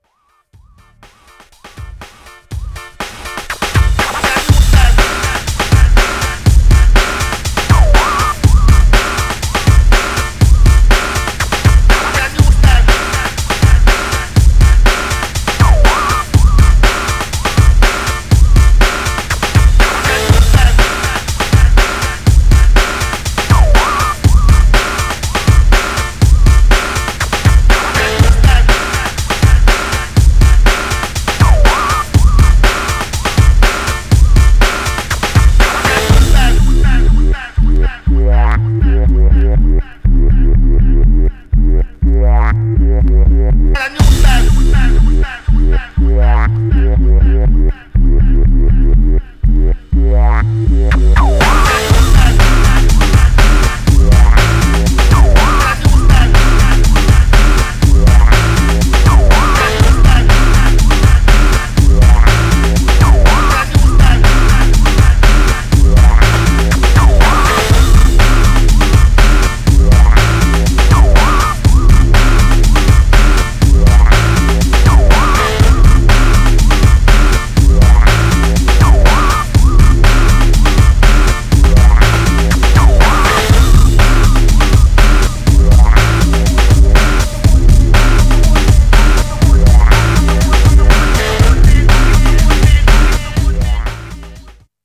Styl: Breaks/Breakbeat